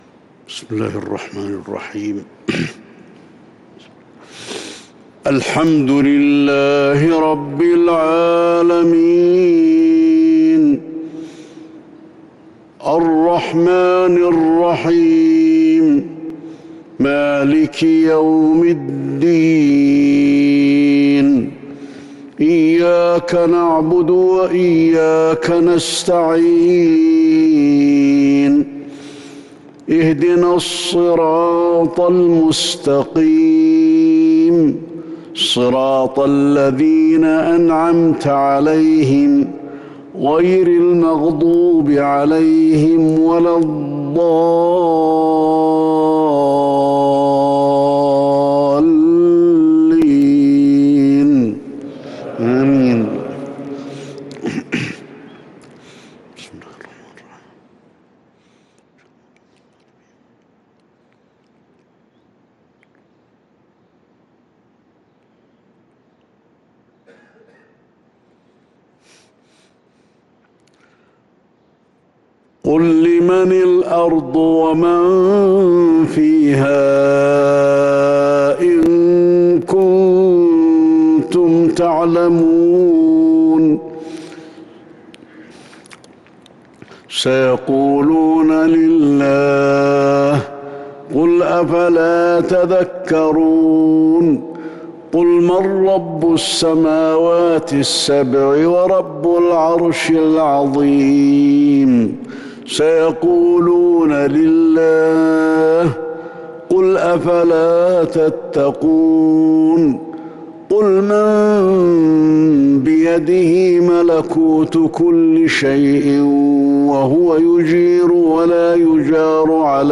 صلاة الفجر للقارئ علي الحذيفي 8 ربيع الأول 1443 هـ